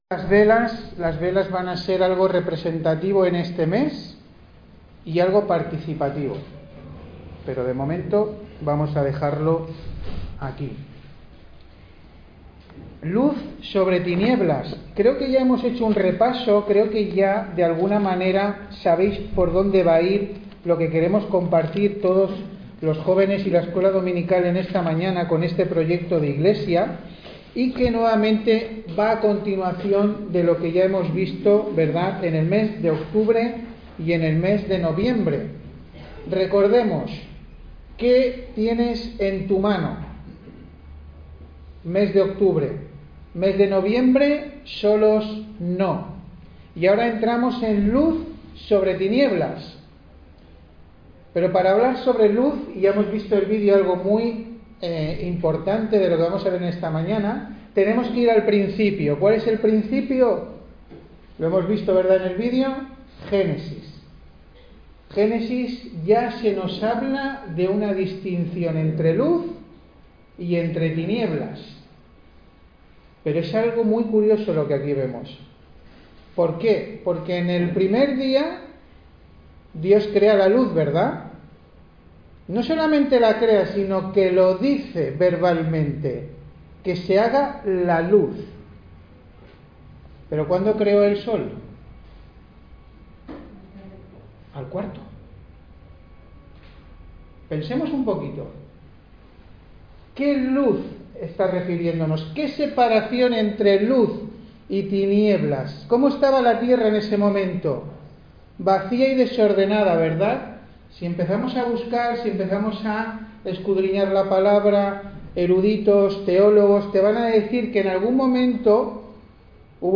Service Type: Culto Dominical